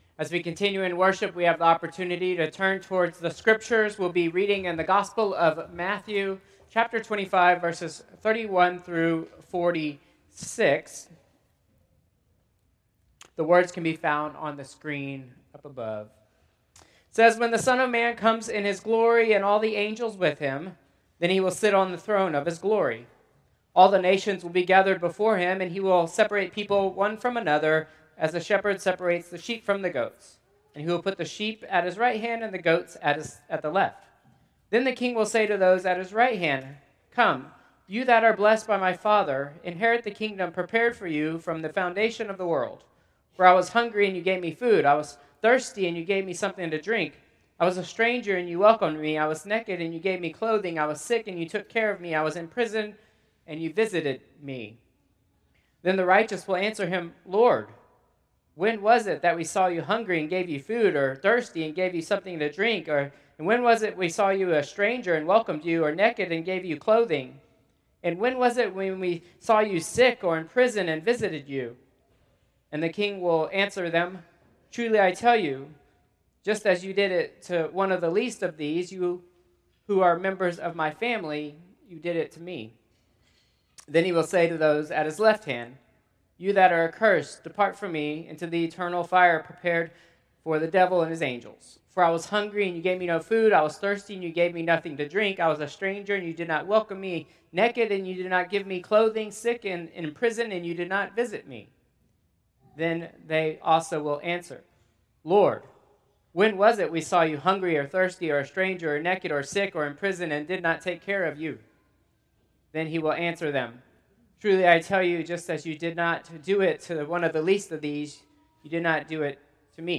Traditional Service 9/7/2025